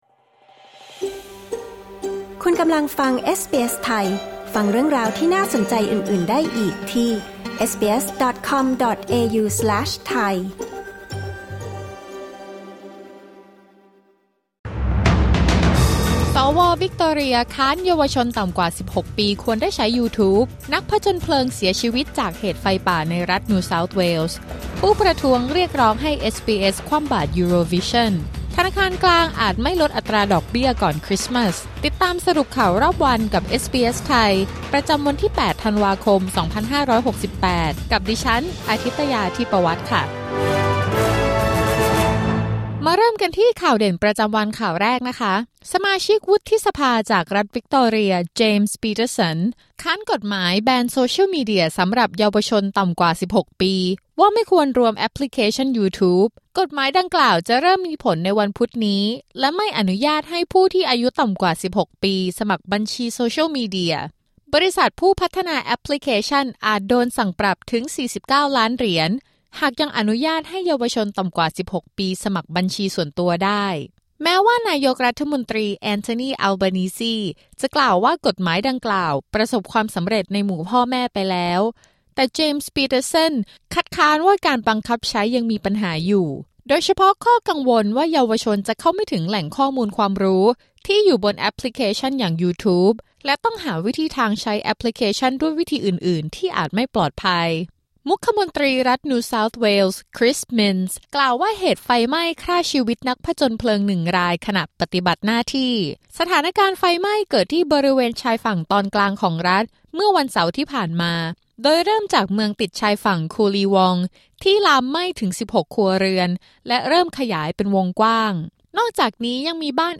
สรุปข่าวรอบวัน จาก เอสบีเอส ไทย Credit: Timon Reinhard via Unsplash, SBS Thai